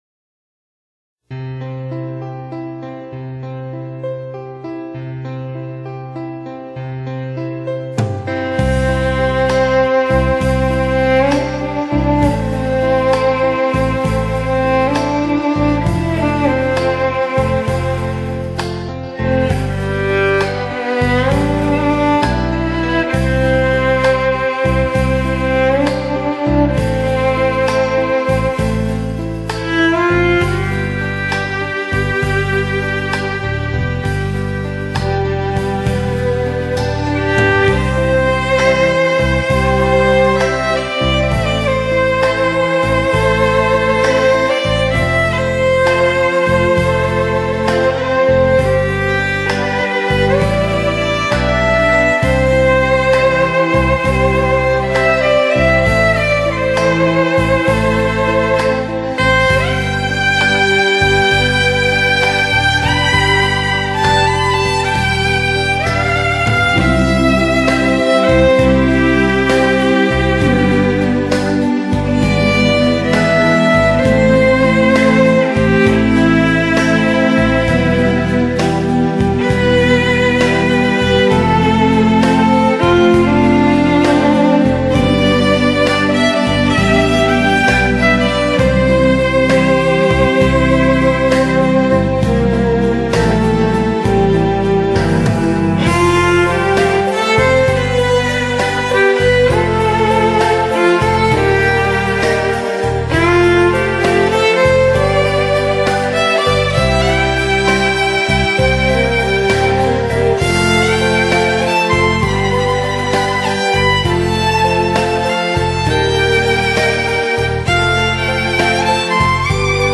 Musica Instrumental - La Sombra del Amor (2).mp3